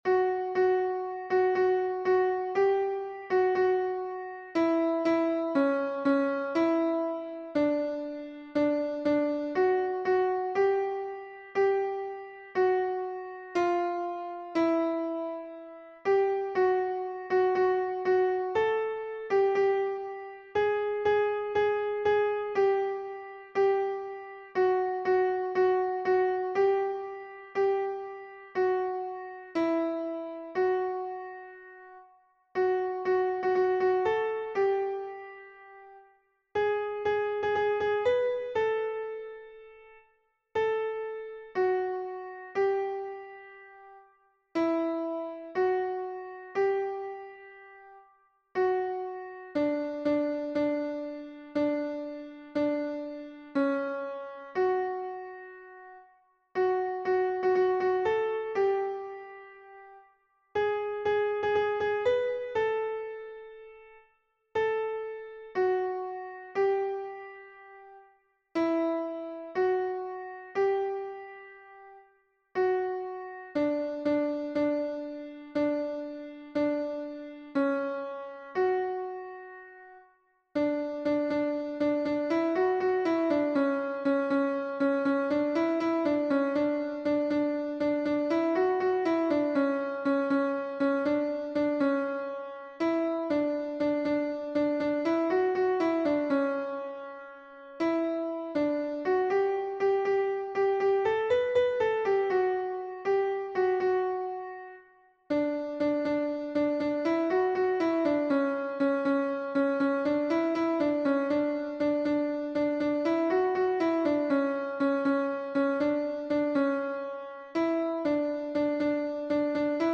How Excellent Is Thy Loving Kindness — Alto Audio.
How_Excellent_Is_Thy_Loving_Kindness_alto.mp3